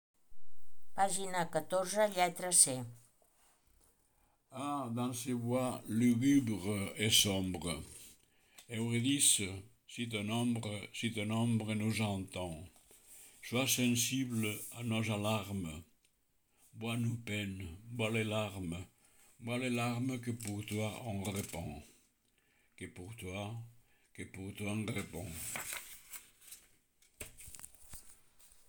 ** AUDIOS PRONUNCIACIÓ TEXT**